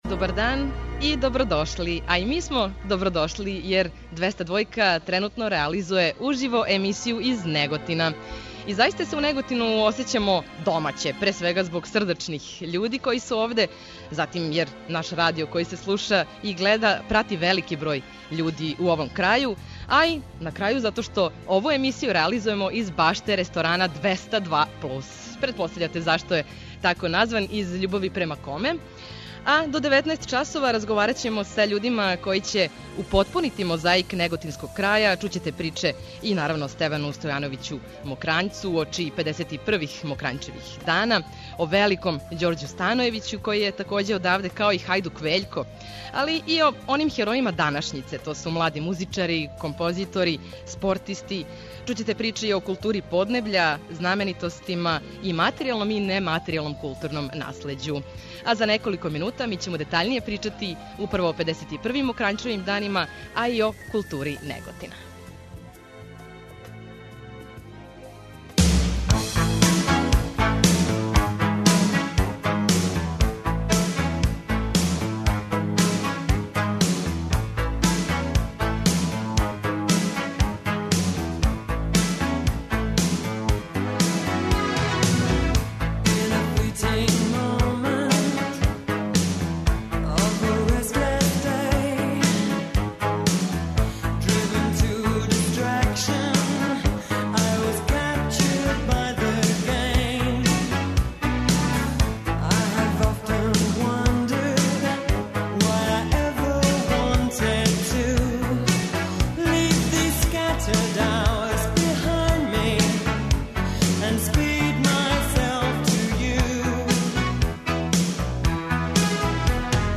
Визија - уживо из Неготина
Двестадвојка уживо из Неготина.